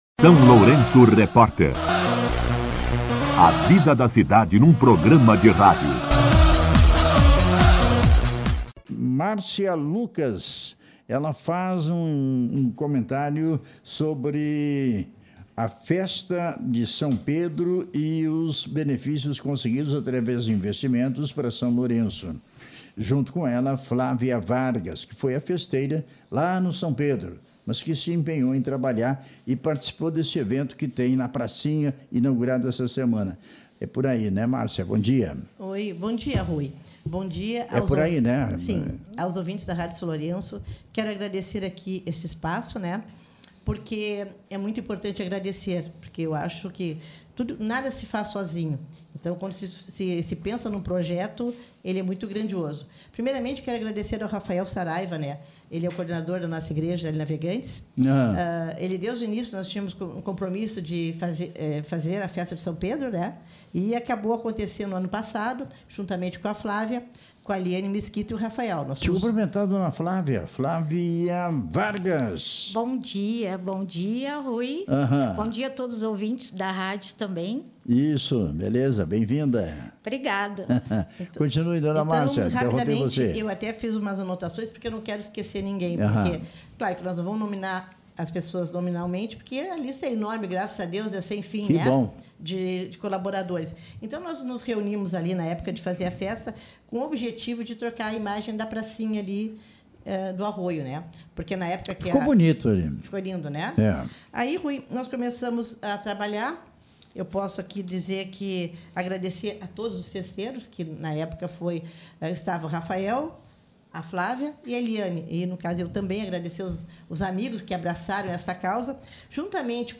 A vereadora Márcia Lucas (PT) participou do SLR RÁDIO, na manhã desta quarta-feira (28), onde falou sobre as emendas impositivas do mandato previstas para 2026.
Entrevista com a Vereadora Márcia Lucas